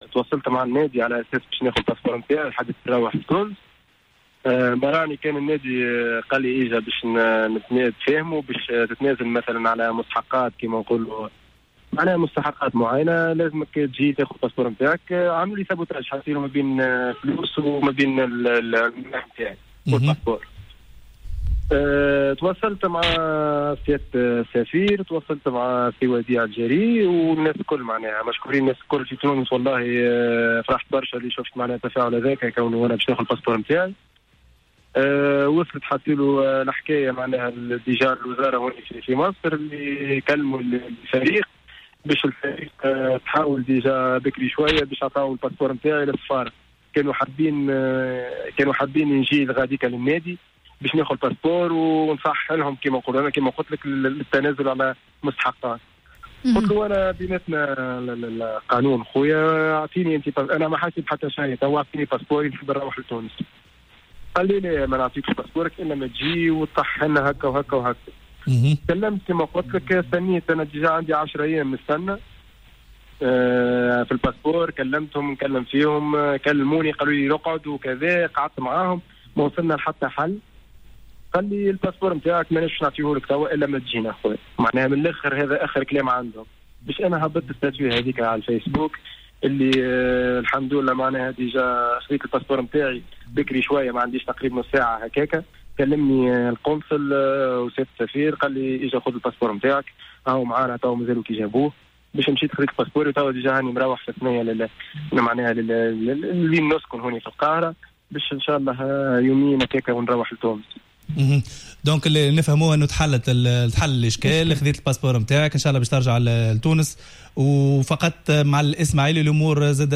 أكد اللاعب لسعد الجزيري في مداخلة في حصة "راديو سبور" أن فريق الإسماعيلي المصري قد حجز جواز سفره لإرغامه على التخلي على مستحقاته المتخلدة لدى الفريق بعد نهاية العلاقة بين الطرفين.